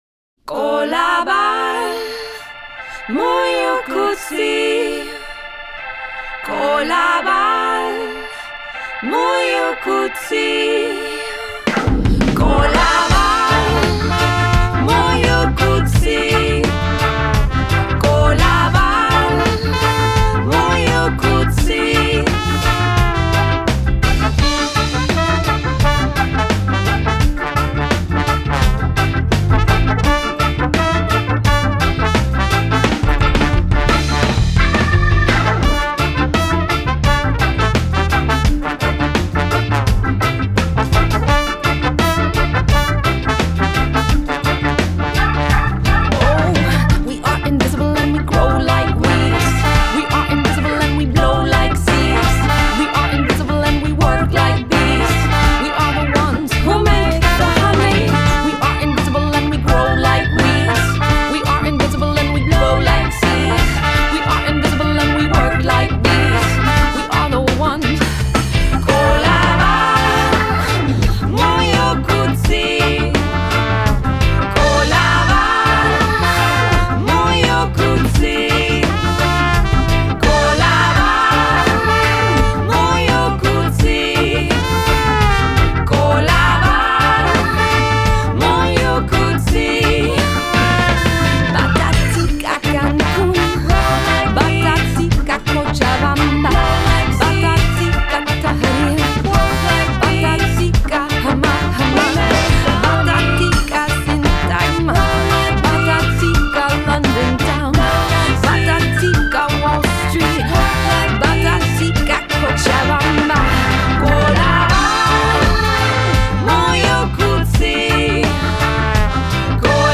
Genre: Folk-jazz, chanson, world, reggae